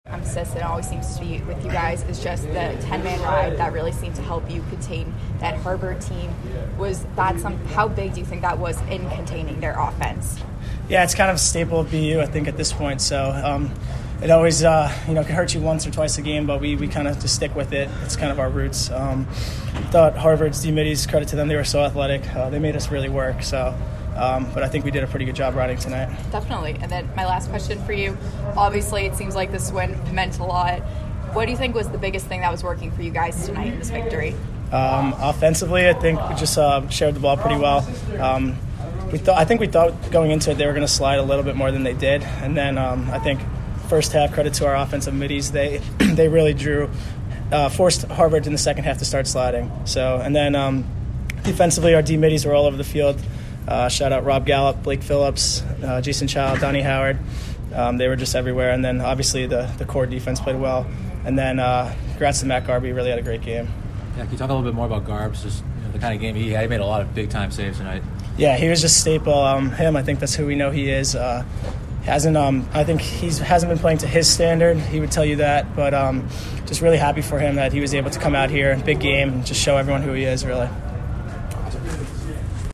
Men's Lacrosse / Harvard Postgame Interview